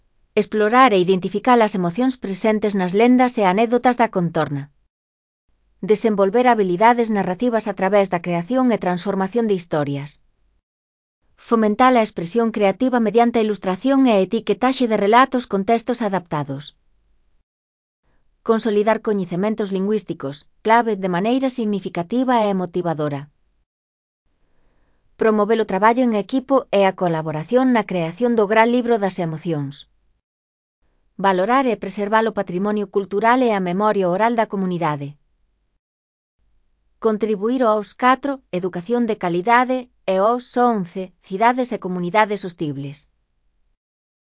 Elaboración propia (proxecto cREAgal) con apoio de IA voz sintética xerada co modelo Celtia.